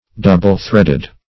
Search Result for " double-threaded" : The Collaborative International Dictionary of English v.0.48: Double-threaded \Dou"ble-thread`ed\, a. 1. Consisting of two threads twisted together; using two threads.